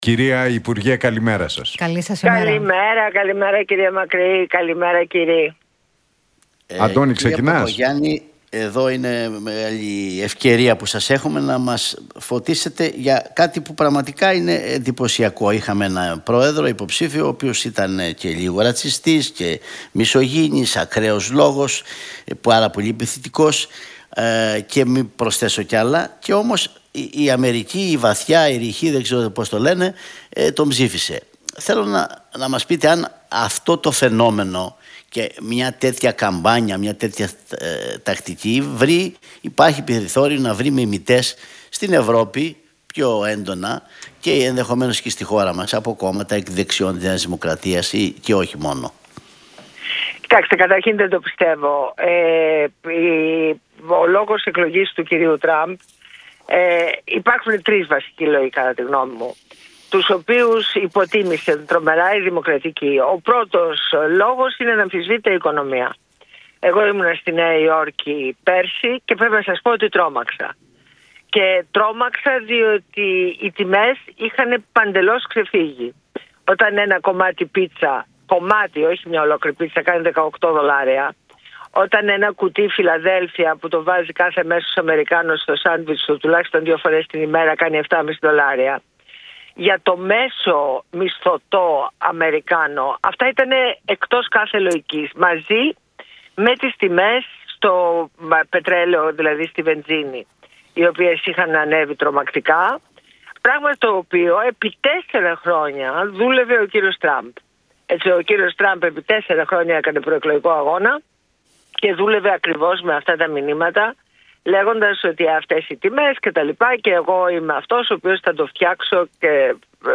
Για την άφιξη του Τούρκου υπουργού Εξωτερικών, Χακάν Φιντάν στην Αθήνα, τα ελληνοτουρκικά και την εκλογή Τραμπ μίλησε η βουλευτής της ΝΔ, Ντόρα Μπακογιάννη
από την συχνότητα του Realfm 97.8.